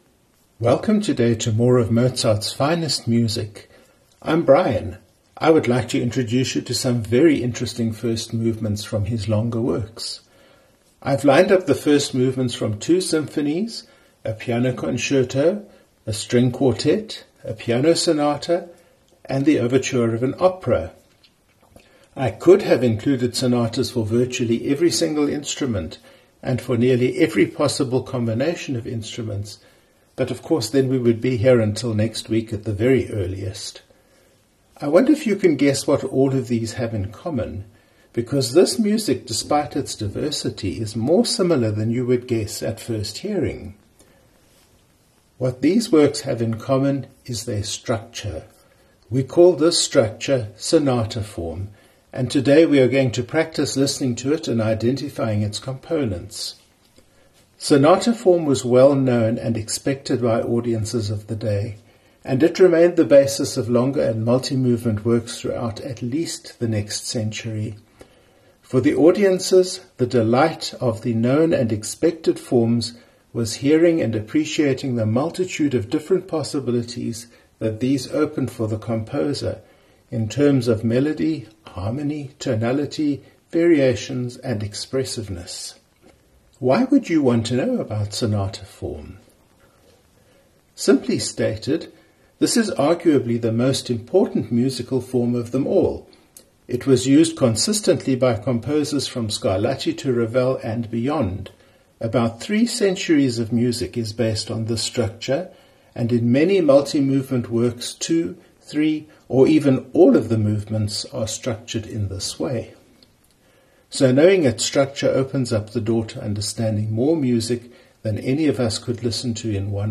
Performed by an unnamed Orchestra and conductor
Performed by Alfred Brendel
Performed by the Emerson String Quartet